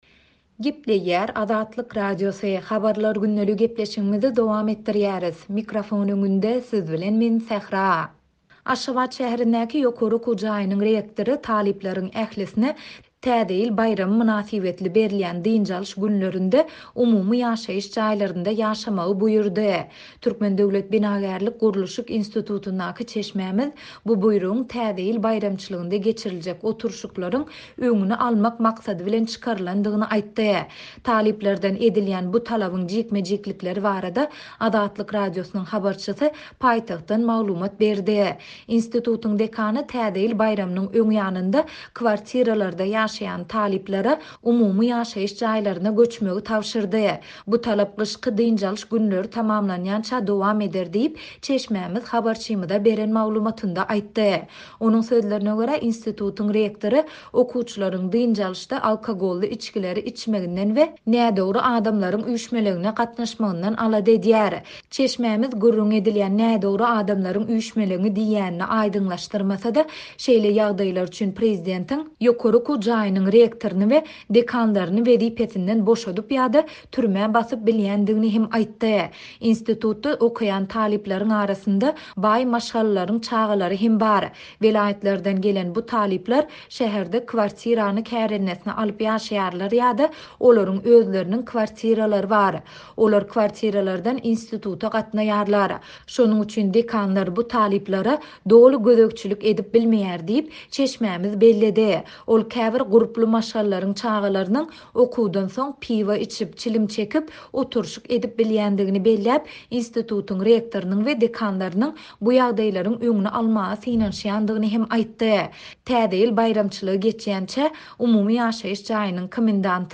Talyplardan edilýän bu talabyň jikme-jiklikleri barada Azatlyk Radiosynyň habarçysy paýtagtdan maglumat berýär.